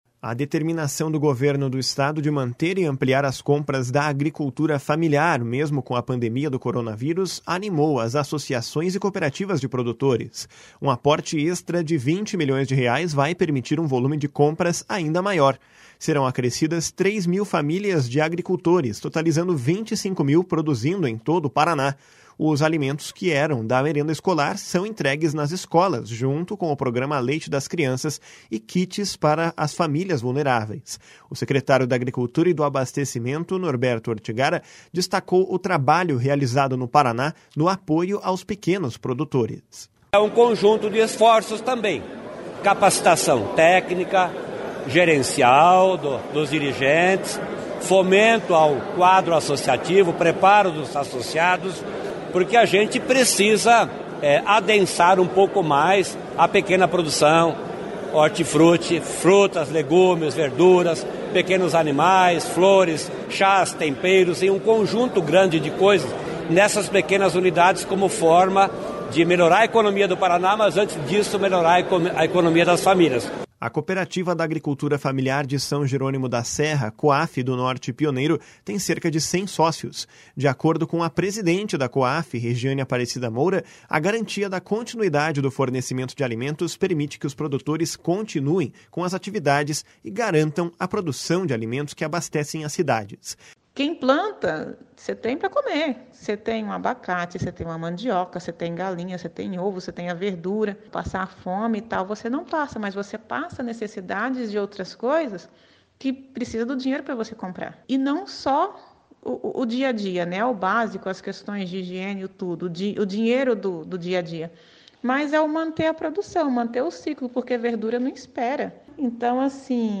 // SONORA NORBERTO ORTIGARA //